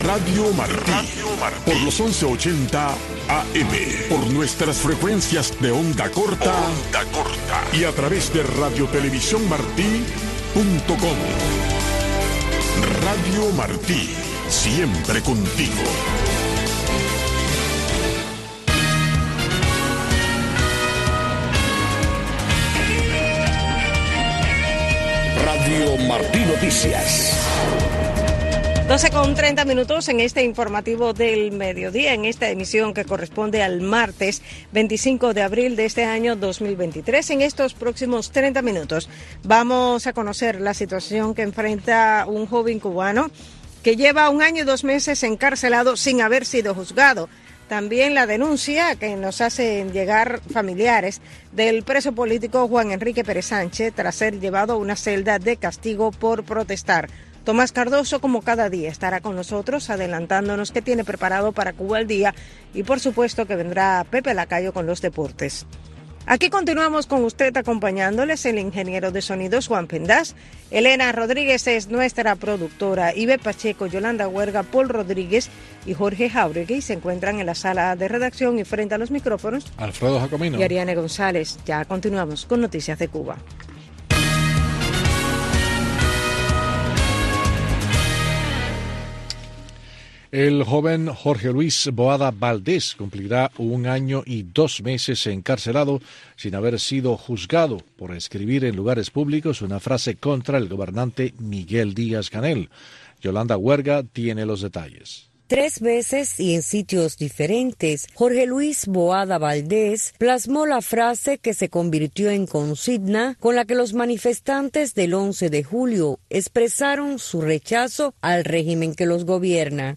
Noticiero de Radio Martí 12:00 PM | Segunda media hora